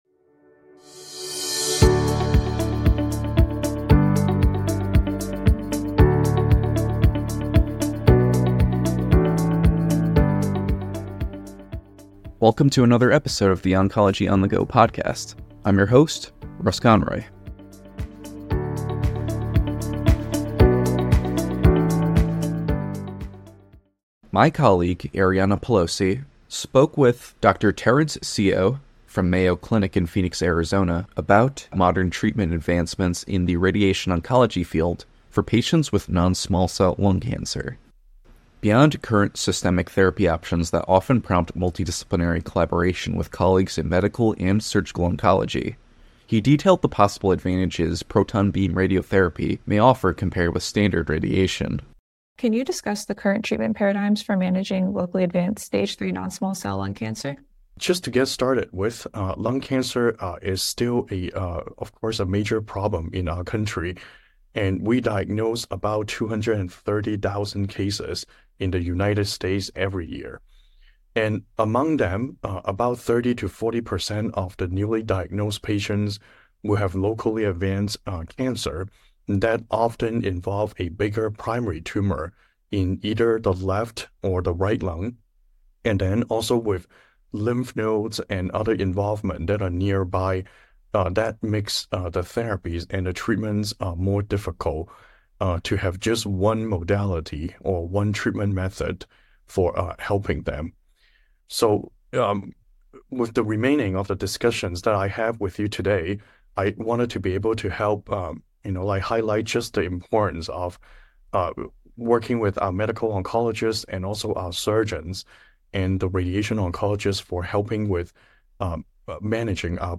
In a conversation with CancerNetwork®